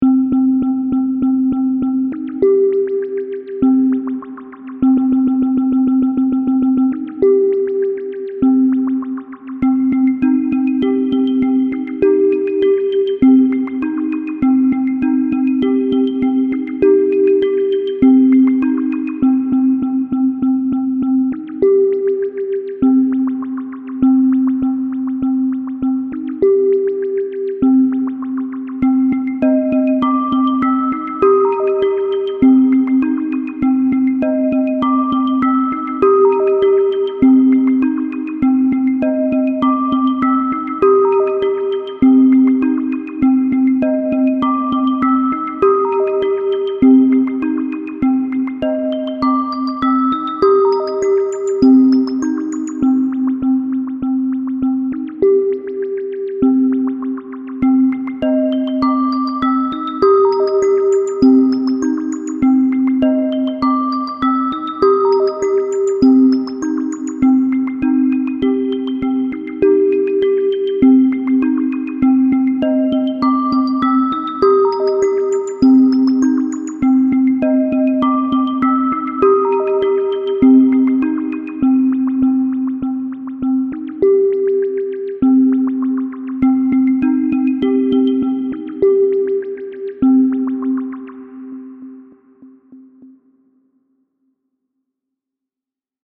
Pieza de Ambient Techno
Música electrónica
tecno
melodía
repetitivo
sintetizador